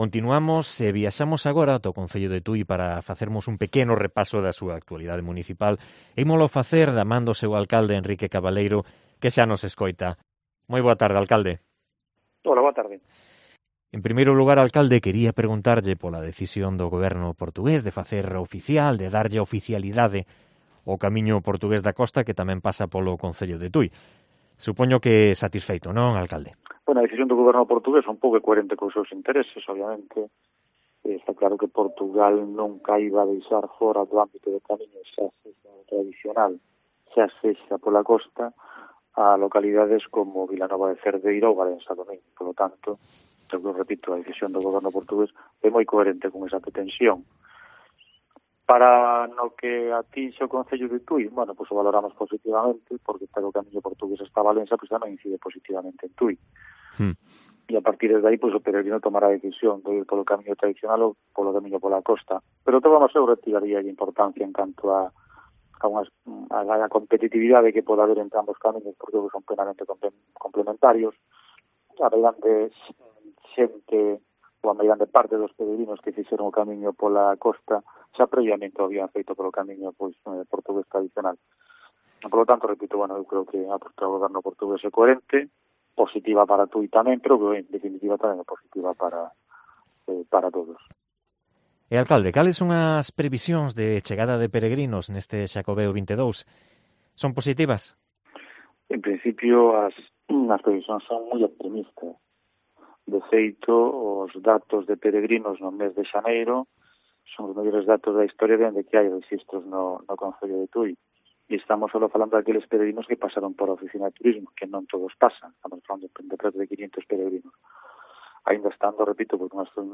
Entrevista a Enrique Cabaleiro, alcalde de Tui